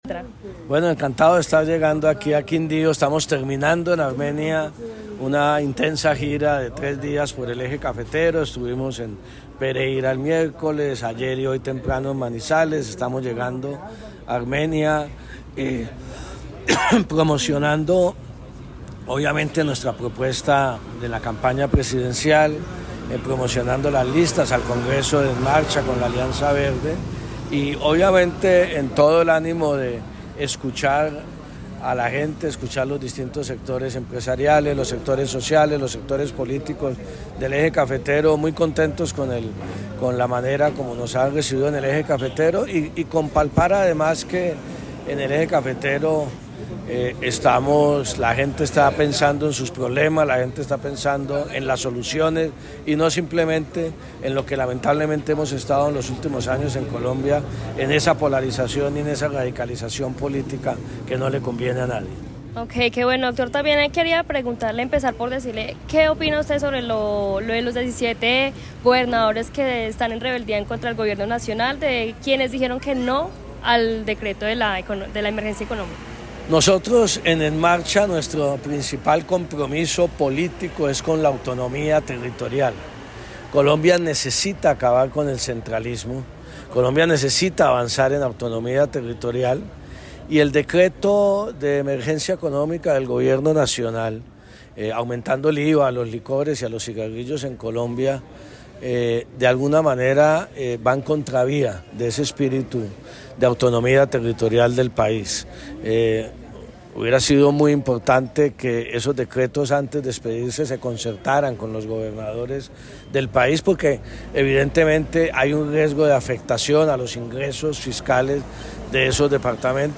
Juan Fernando Cristo, candidato presidencial de visita en Armenia